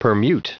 Prononciation du mot permute en anglais (fichier audio)
Prononciation du mot : permute